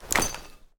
grenade_throw.ogg